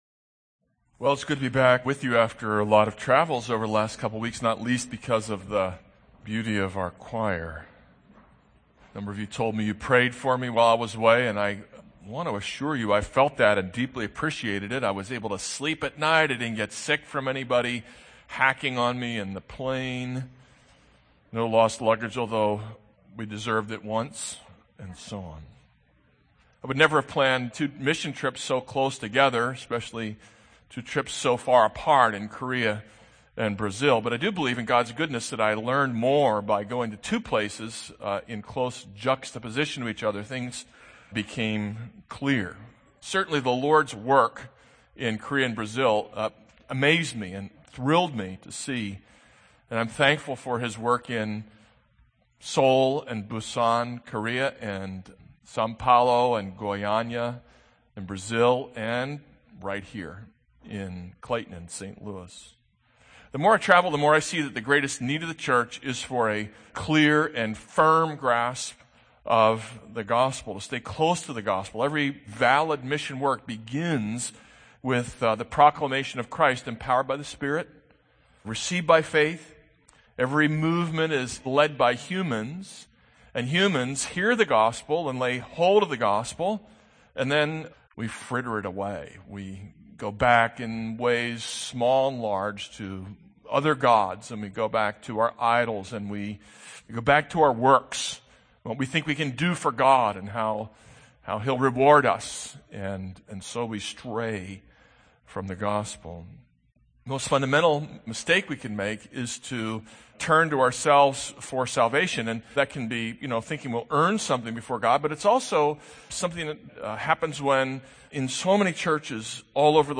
This is a sermon on Romans 4:1-3.